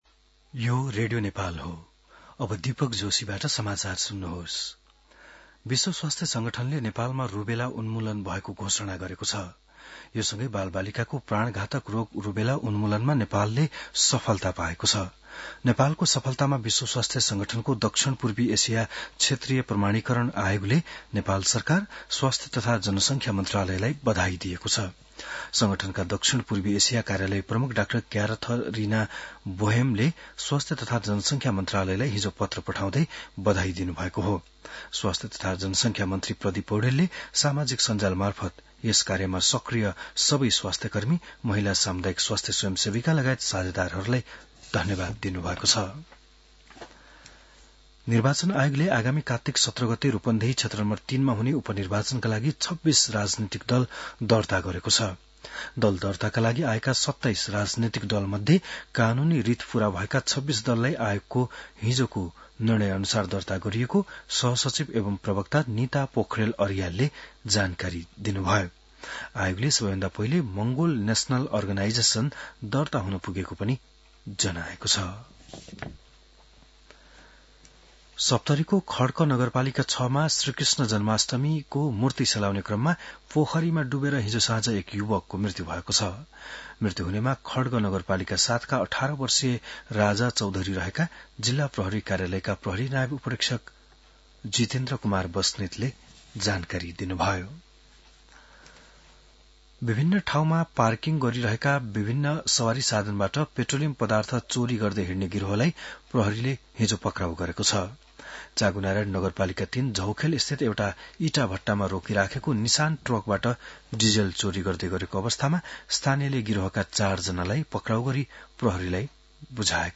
An online outlet of Nepal's national radio broadcaster
बिहान १० बजेको नेपाली समाचार : ३ भदौ , २०८२